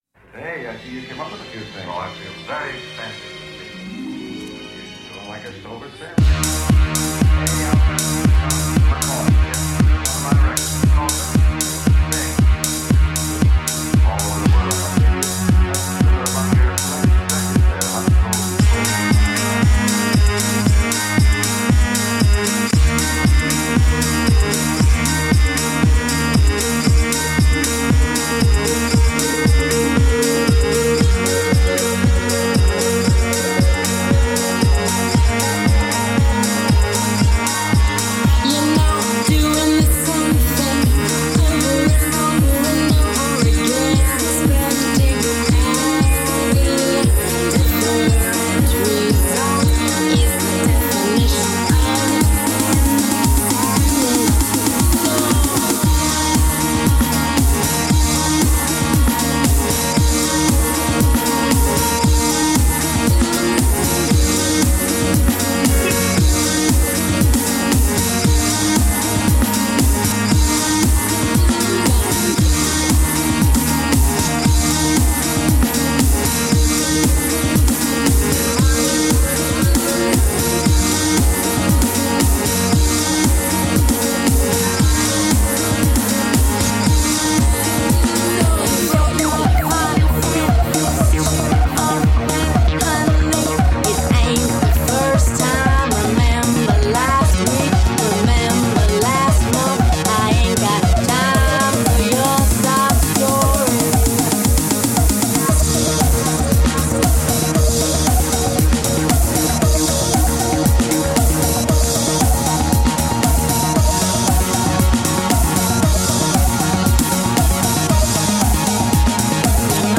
Glamorously femme electropop .
rich, lush vocals
Electro Rock, Pop, Indie Rock
Woman Singing Electro Pop